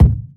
Kick30.wav